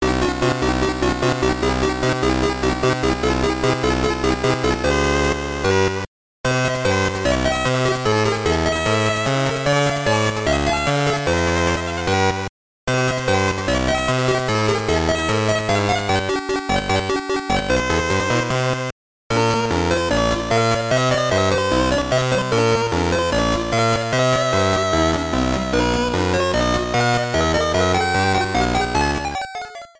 Title screen music, trimmed to 30 seconds.